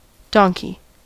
Ääntäminen
IPA : /ˈdʌŋki/
IPA : /ˈdɒŋki/